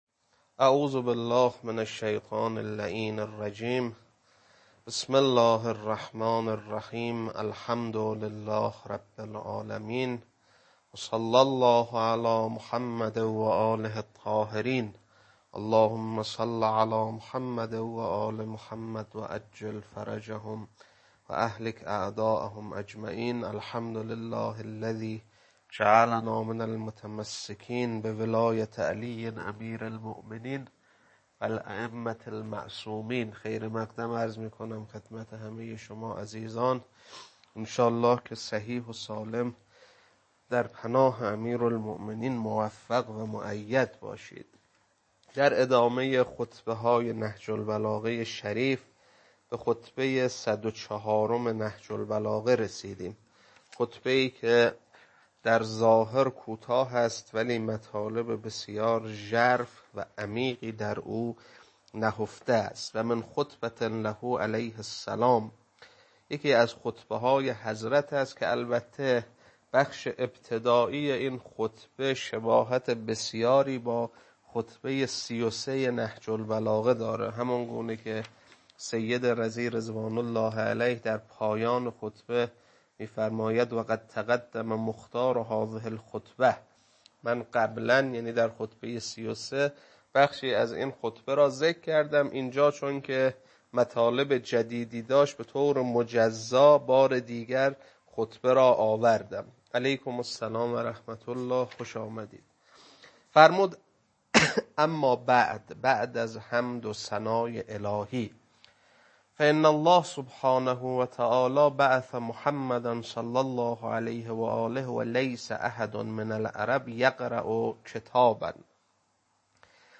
خطبه-104.mp3